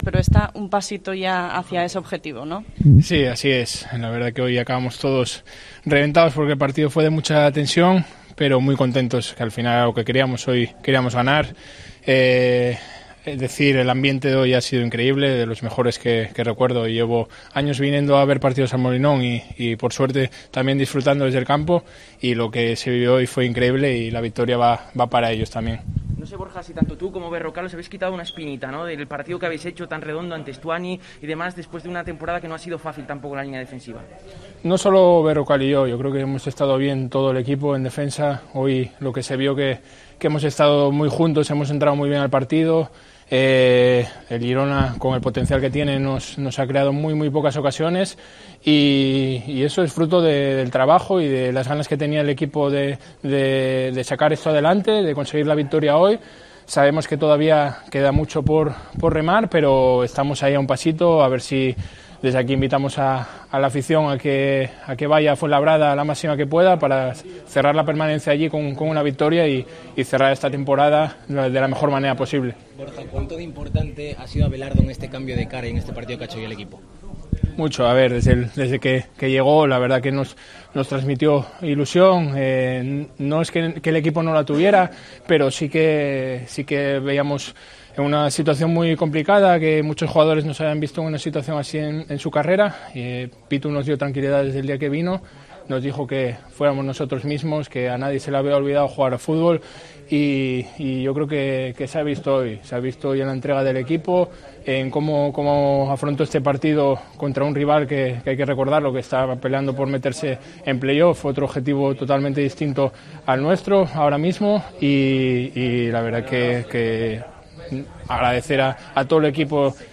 Borja López en zona mixta (post Girona)